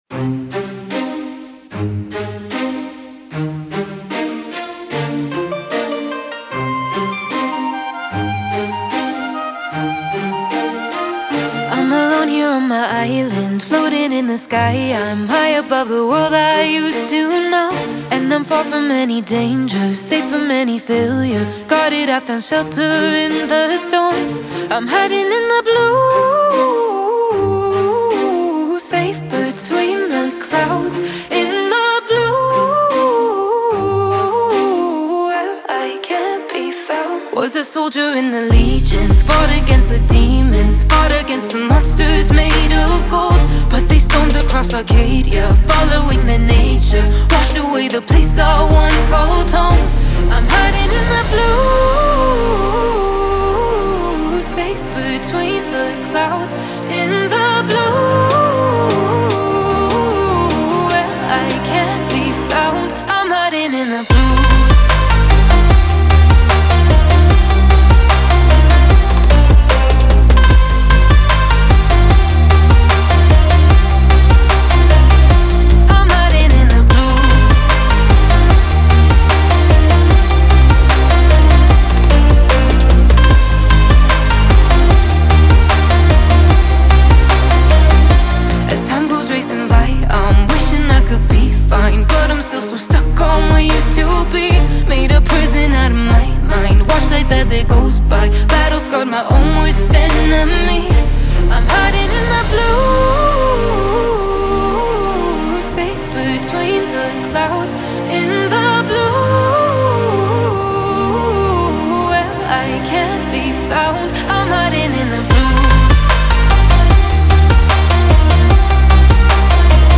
это захватывающая трек в жанре электронной музыки